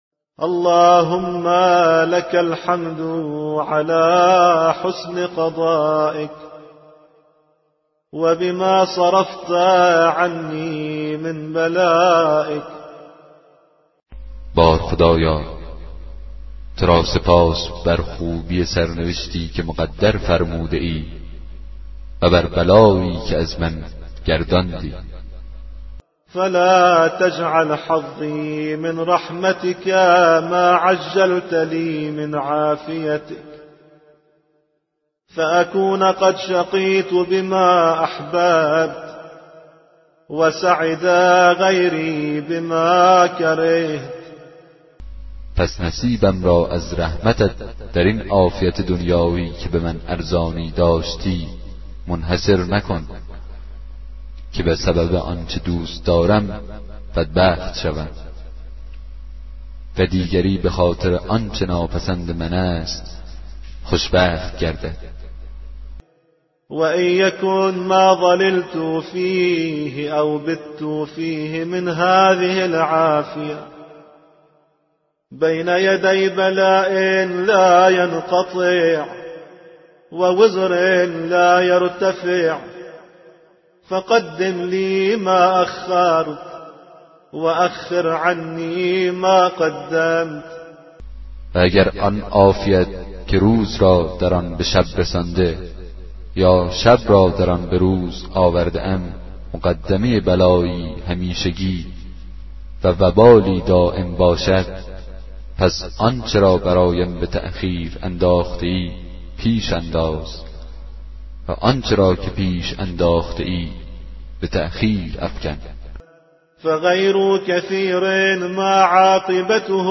کتاب صوتی دعای 18 صحیفه سجادیه